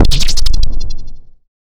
MOOGPRC2.wav